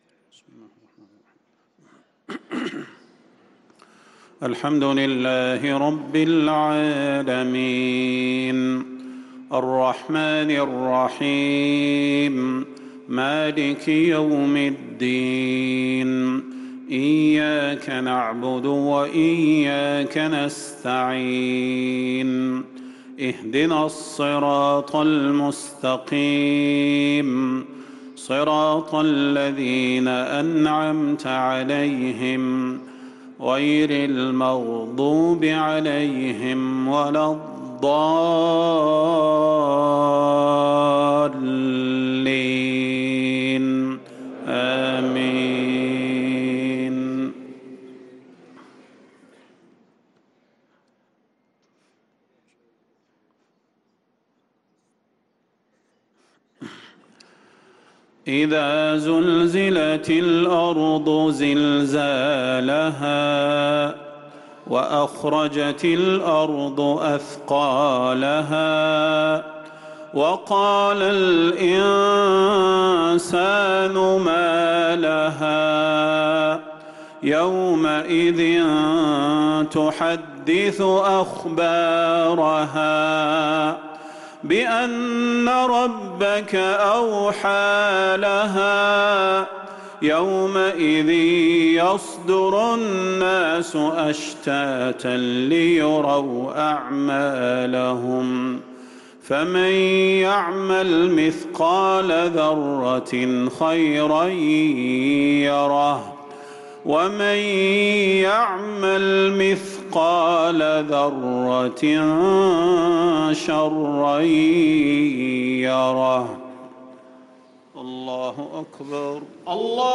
صلاة المغرب للقارئ بندر بليلة 9 رجب 1445 هـ
تِلَاوَات الْحَرَمَيْن .